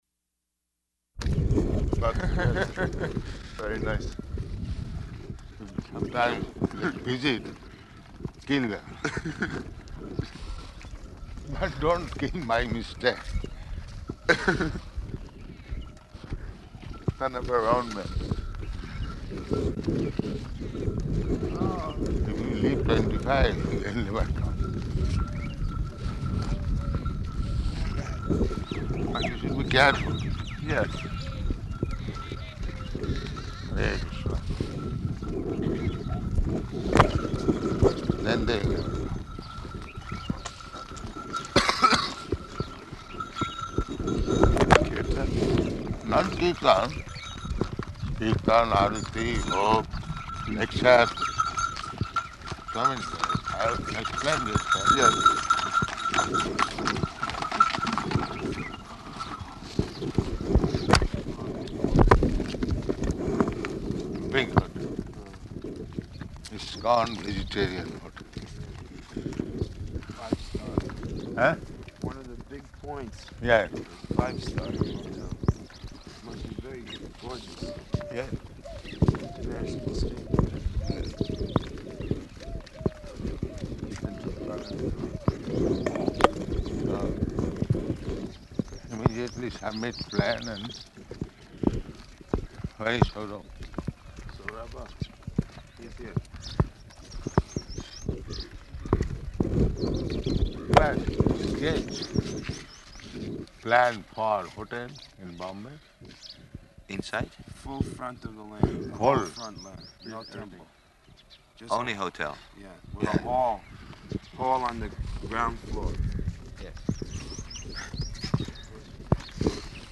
-- Type: Walk Dated: March 18th 1974 Location: Vṛndāvana Audio file